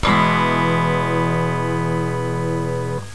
virtual guitar